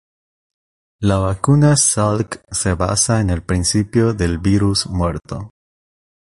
Pronunciado como (IPA)
/ˈbiɾus/